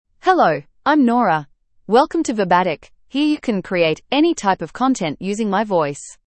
FemaleEnglish (Australia)
Nora is a female AI voice for English (Australia).
Voice sample
Listen to Nora's female English voice.
Female
English (Australia)